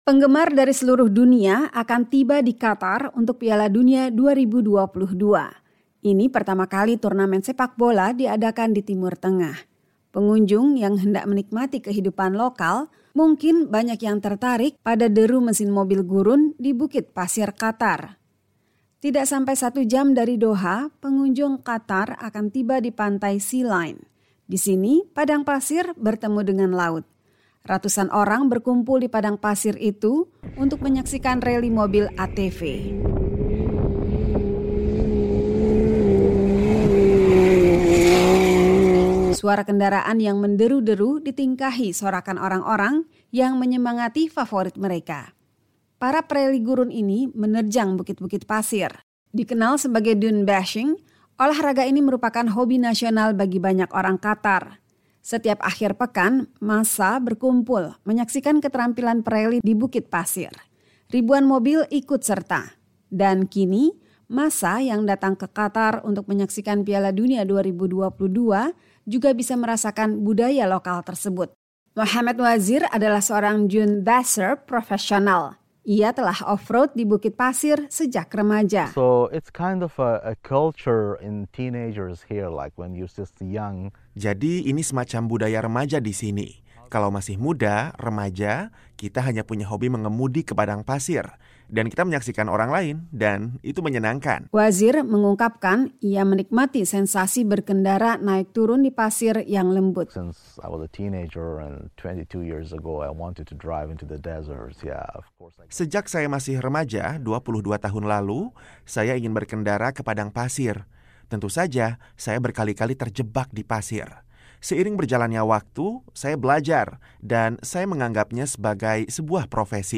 Pengunjung yang hendak menikmati kehidupan lokal, mungkin banyak yang tertarik pada deru mesin mobil gurun di bukit pasir Qatar.
Suara kendaraan yang menderu-deru ditingkahi sorakan orang-orang yang menyemangati favorit mereka.